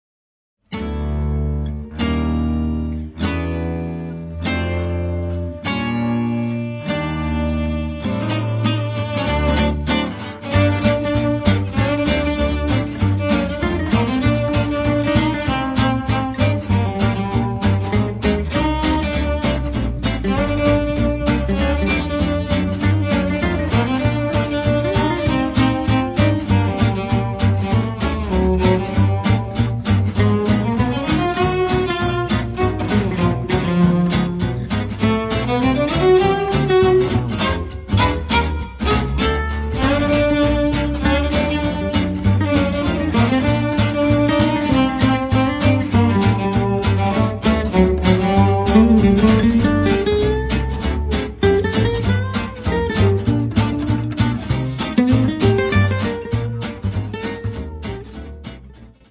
guitar
double bass
cello
clarinet